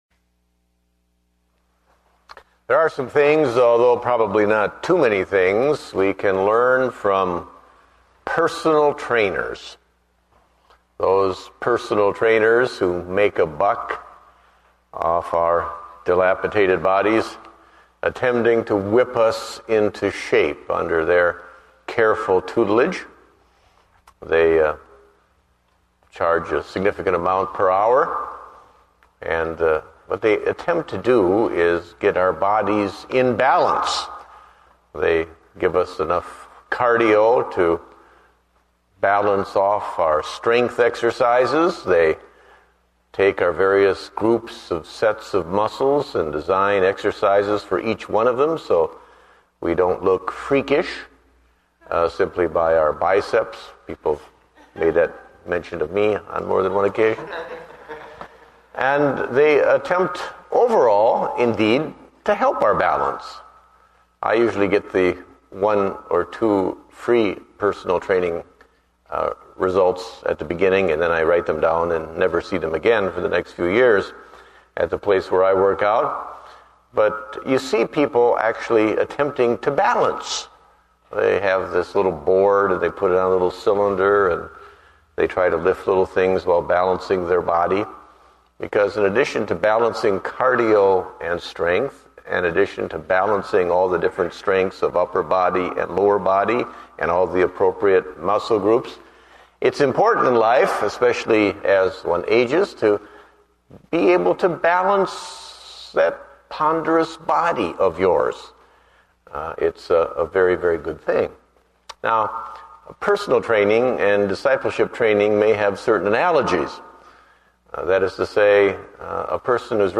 Date: April 5, 2009 (Evening Service)